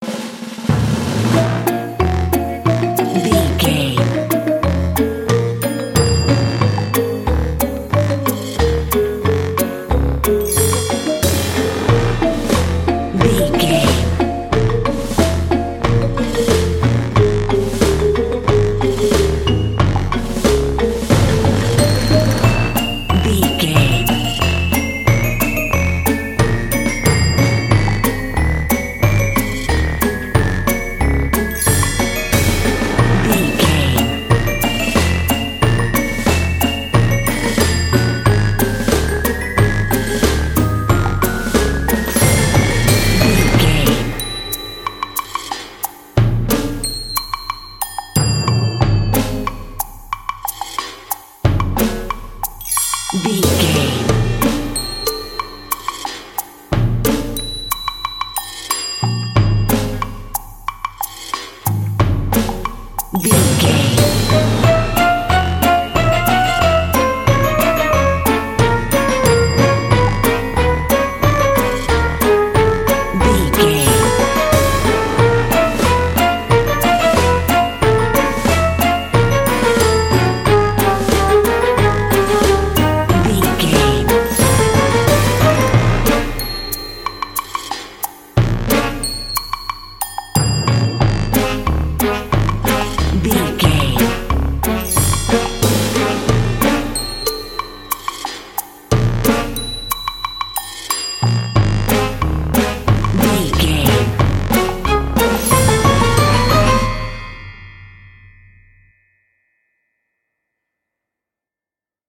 Aeolian/Minor
B♭
percussion
synthesiser
horns
strings
silly
circus
goofy
comical
cheerful
perky
Light hearted
quirky